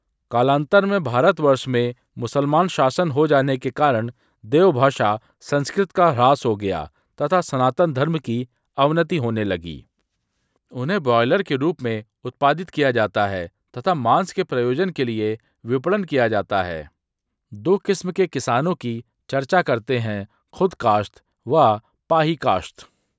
TTS_multilingual_audios